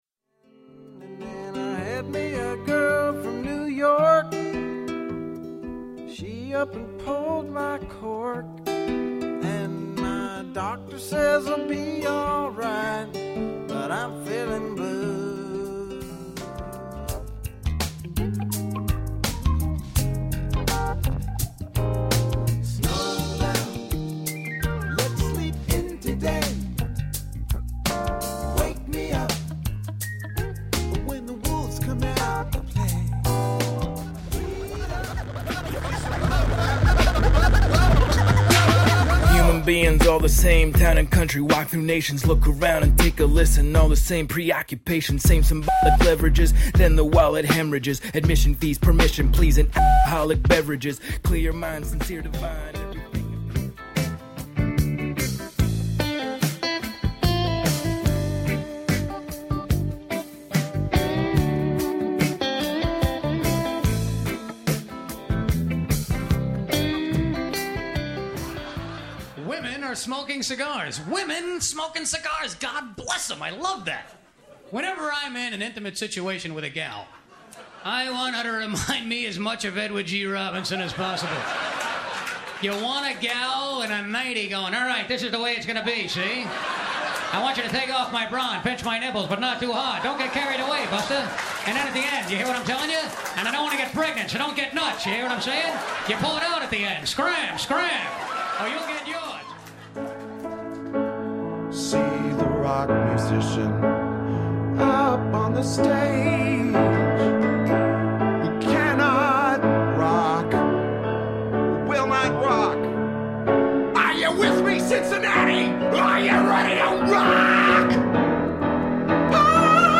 I spliced short samples from each one into a single track.
He had a smooth, clear voice back then.
Hip hop.
But this version is lean and clean. 5.
A not so great sounding bootleg concert recording.
Electro-pop.